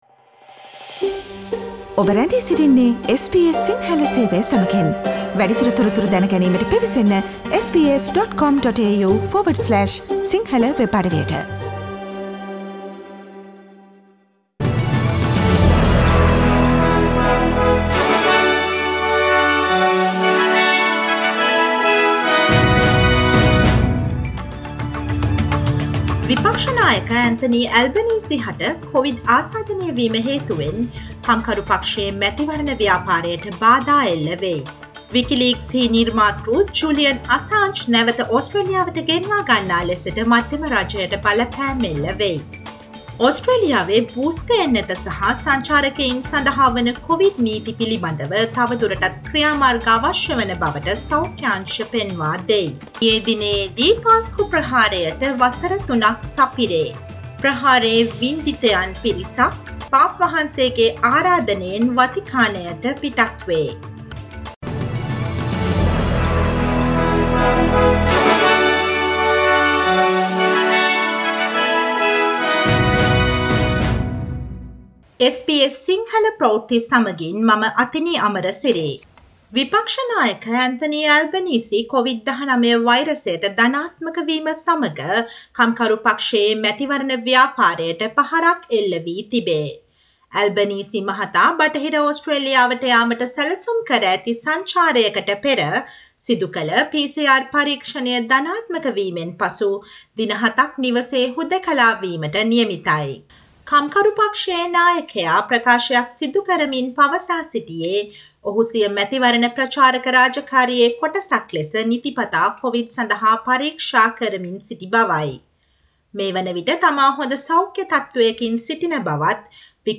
2022 අප්‍රේල් 15 වන සිකුරාදා SBS සිංහල ගුවන්විදුලියේ ප්‍රවෘත්ති ප්‍රකාශයට සවන්දෙන්න.